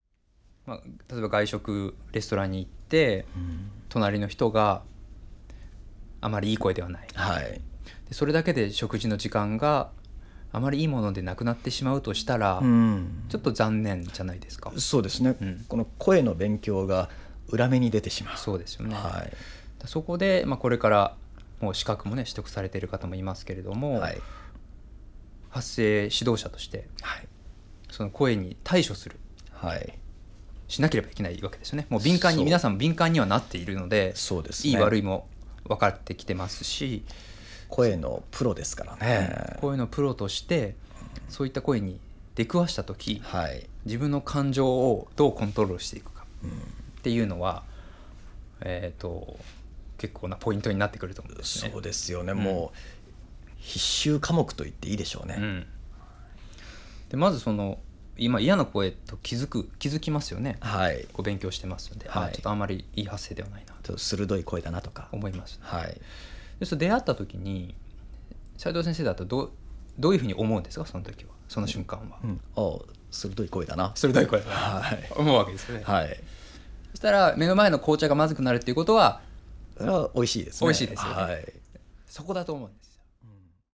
それでは音声講座でお会いしましょう。